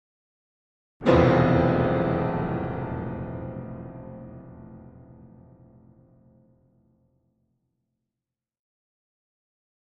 Piano Danger Chord Type B